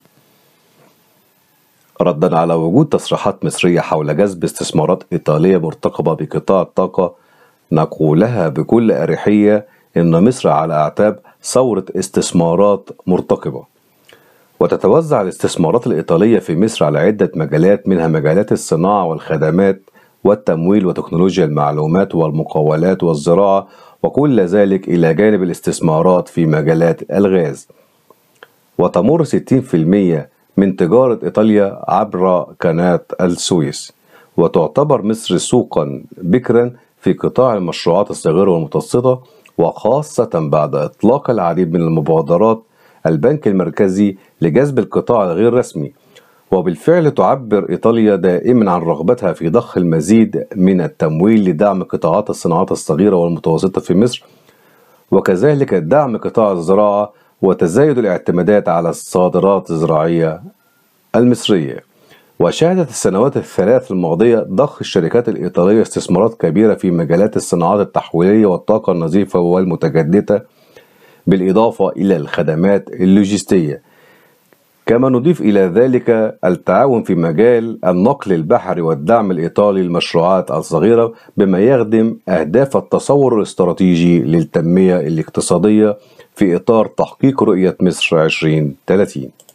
محلل اقتصادي